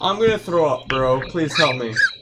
throwup